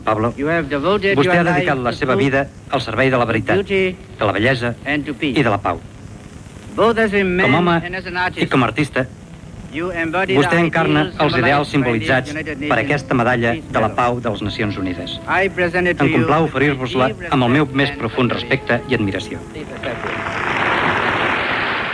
Paraules del secretari general de Nacions Unides U Thant al músic Pau Casals quan va rebre la Medalla de la Pau de les Nacions Unides.
Informatiu